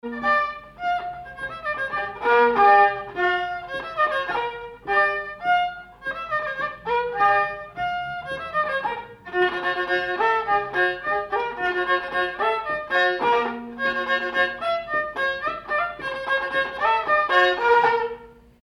danse : polka piquée
circonstance : bal, dancerie
Pièce musicale inédite